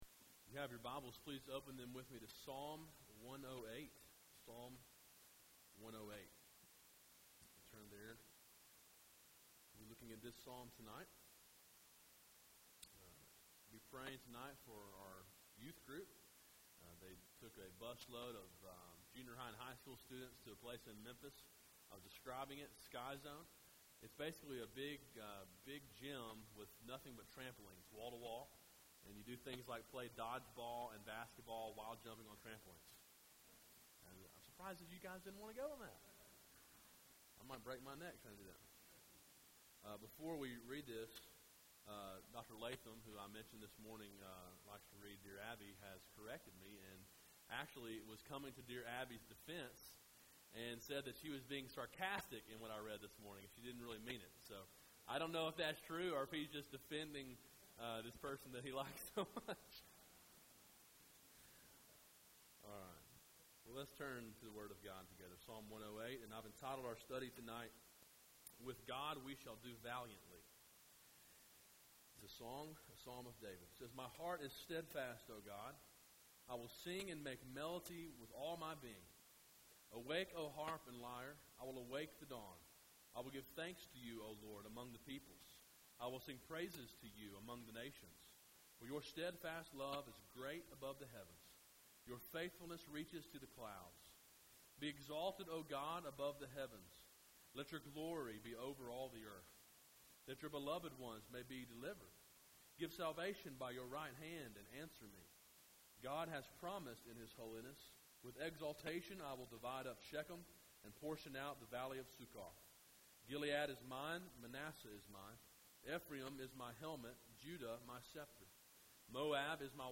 A sermon in a series on the book of Psalms. Preached during the evening service on 10.7.12. Download mp3